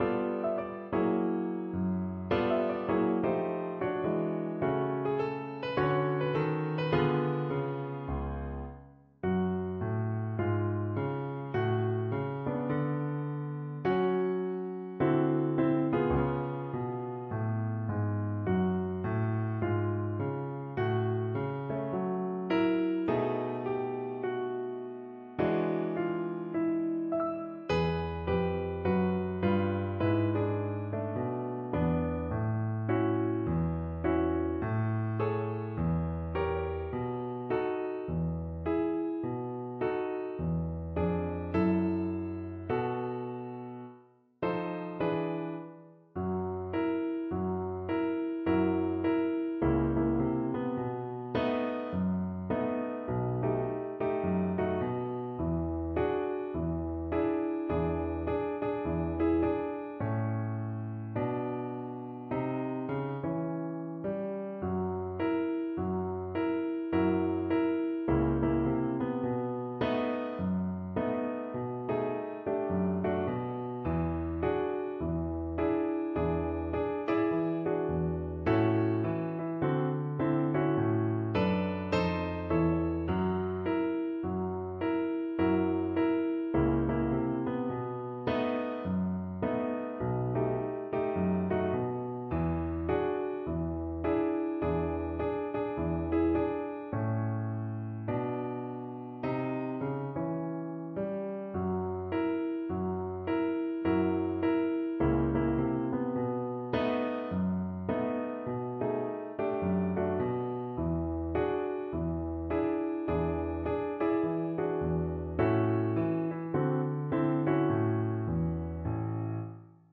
Slowly = 52
2/2 (View more 2/2 Music)
Pop (View more Pop Violin Music)